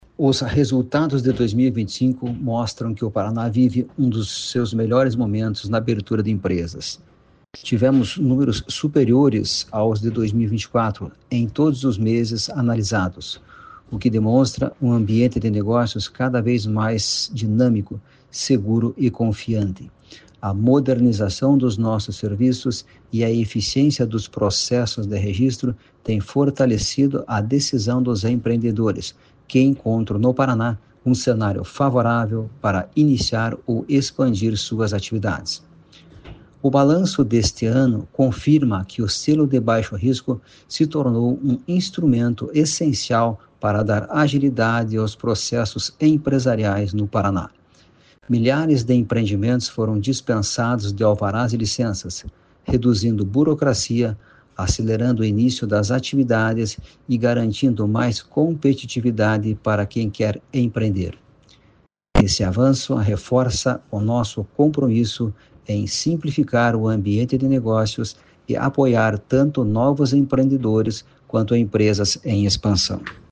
Sonora do presidente da Jucepar, Marcos Rigoni, sobre a abertura de empresas recorde no Paraná em 2025